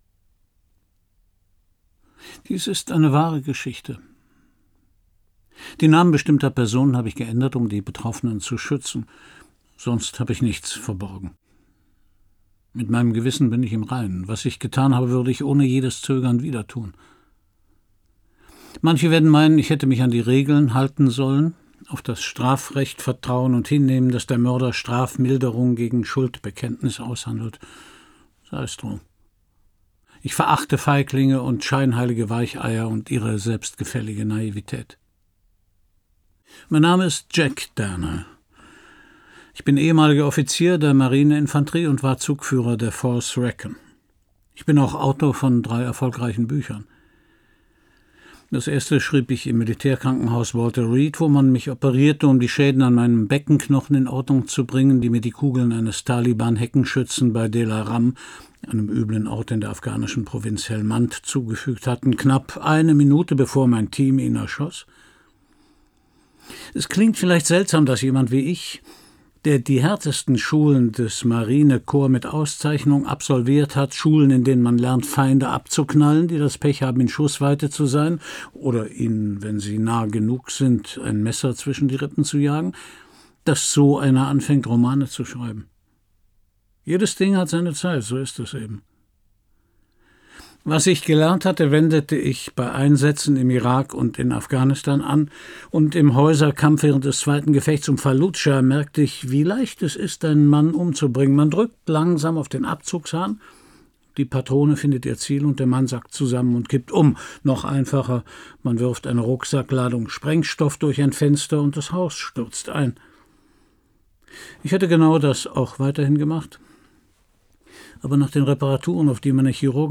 Christian Brückner (Sprecher)
Schlagworte Hörbuch; Krimis/Thriller-Lesung